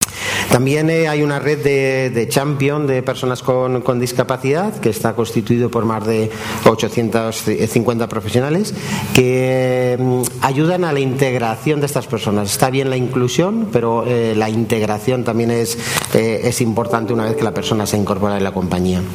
Mesa redonda por la diversidad transversal